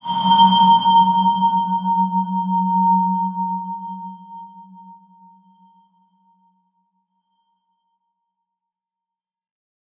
X_BasicBells-F#1-pp.wav